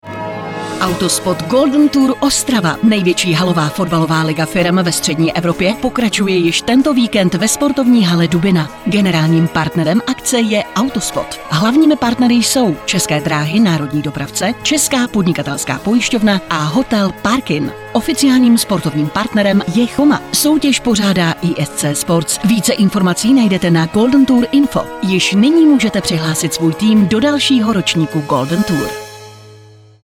Rozhlasový spot Autospot Golden Tour Ostrava 2018